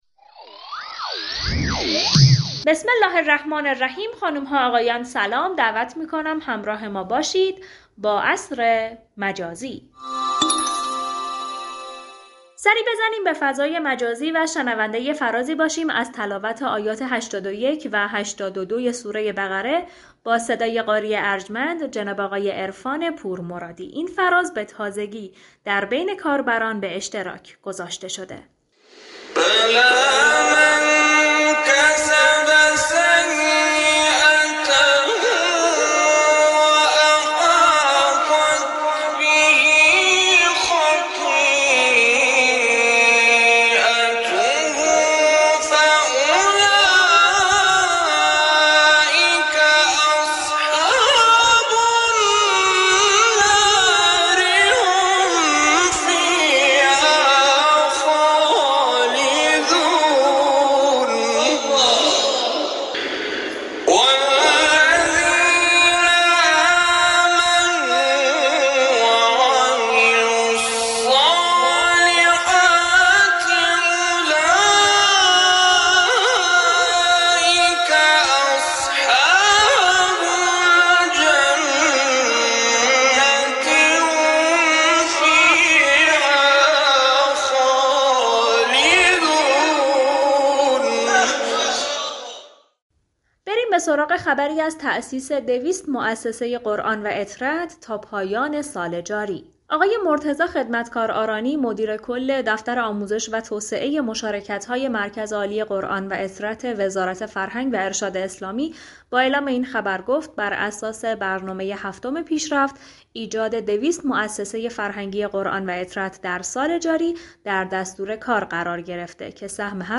تلاوت آیاتی از سوره مباركه بقره